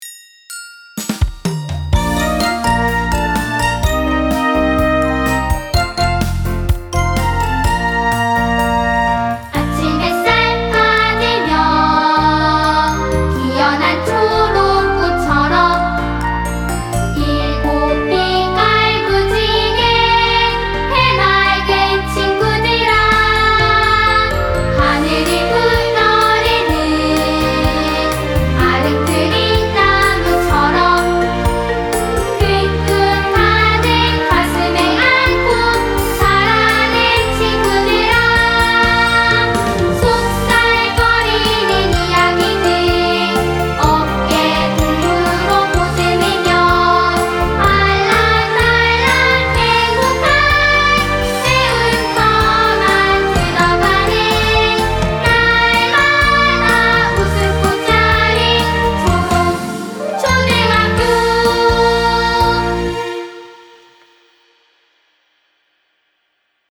초롱초등학교 교가